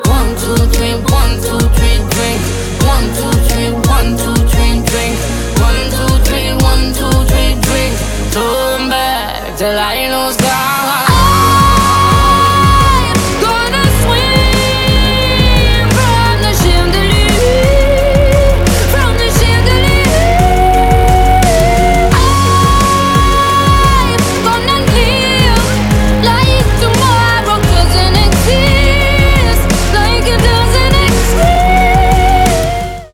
• Качество: 192, Stereo
поп
громкие
vocal